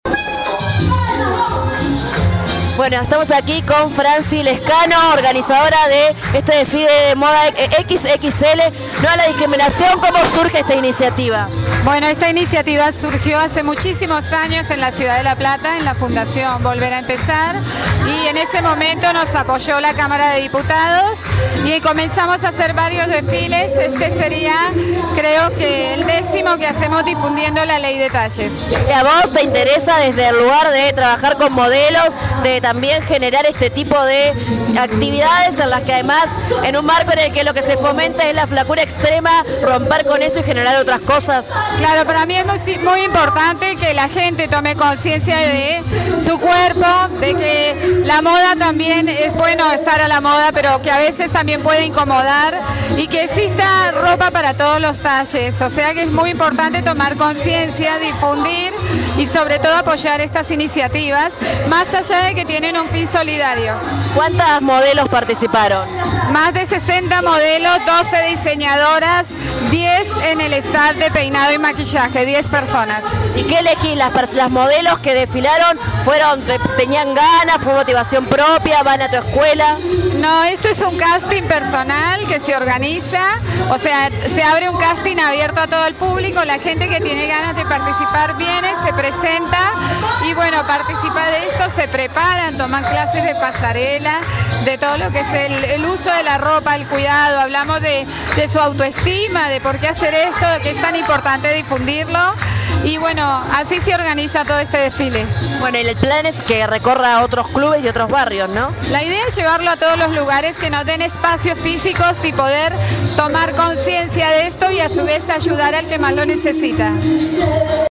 Móvil: